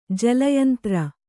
♪ jala yantra